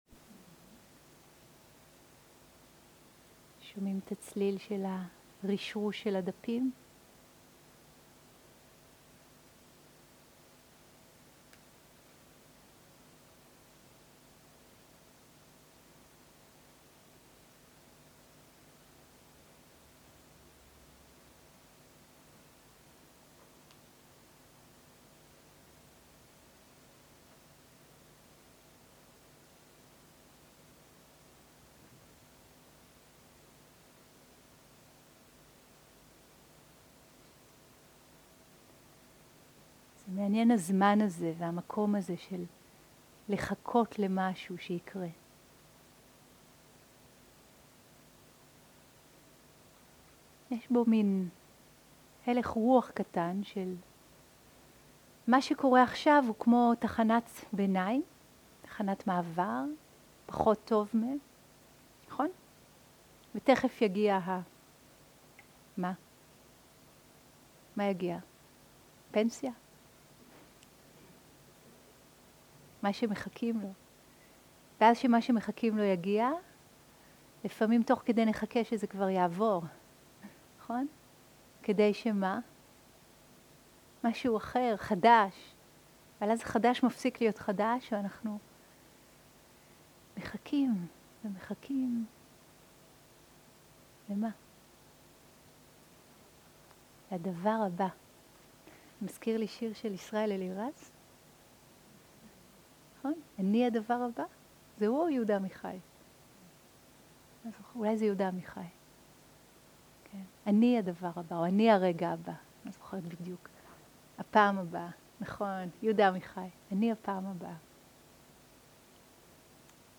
סוג ההקלטה: שיחות דהרמה
עברית איכות ההקלטה: איכות גבוהה מידע נוסף אודות ההקלטה